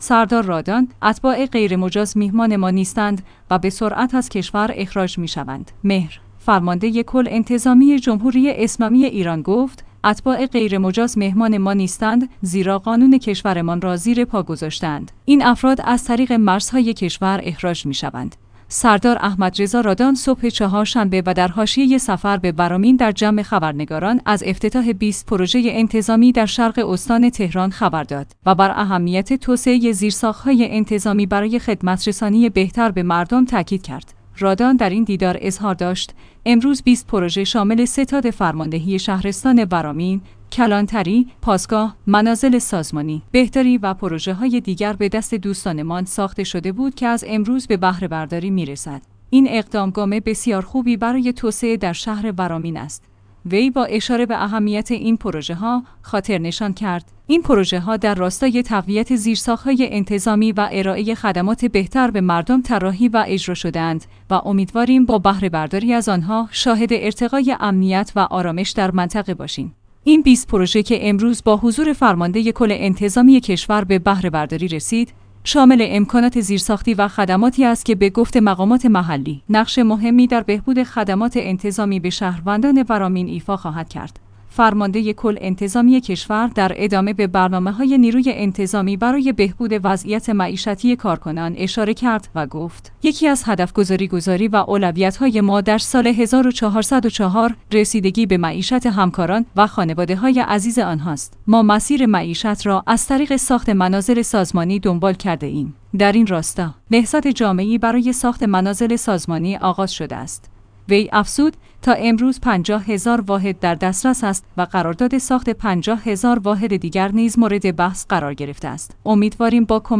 مهر/ فرمانده کل انتظامی جمهوری اسلامی ایران گفت: اتباع غیرمجاز مهمان ما نیستند، زیرا قانون کشورمان را زیر پا گذاشته‌اند، این افراد از طریق مرزهای کشور اخراج می شوند. سردار احمدرضا رادان صبح چهارشنبه و در حاشیه سفر به ورامین در جمع خبرنگاران از افتتاح ۲۰ پروژه انتظامی در شرق استان تهران خبر داد و بر